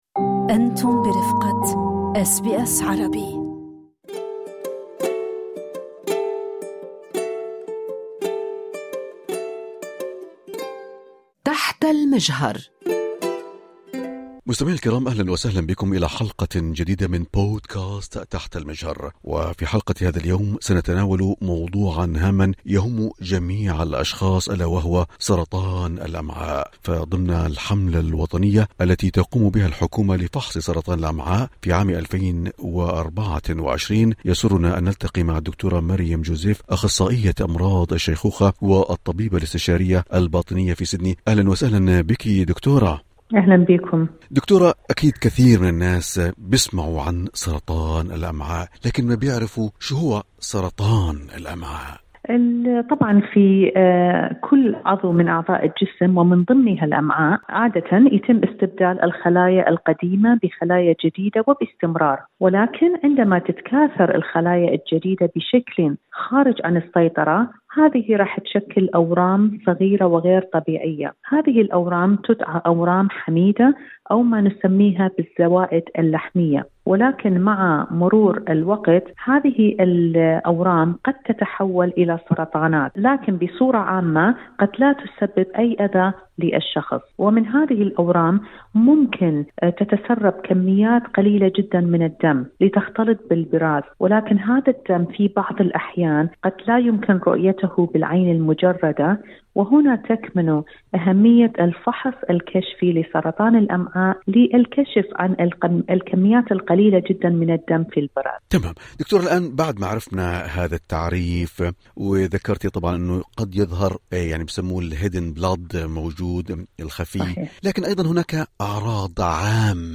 تنويه: المعلومات المقدمة في هذا اللقاء ذات طبيعة عامة، ولا يمكن اعتبارها نصيحة مهنية، نظراً لأن الظروف الفردية قد تختلف من شخص لآخر، ويجب عليكم استشارة خبير مستقل إذا لزم الأمر.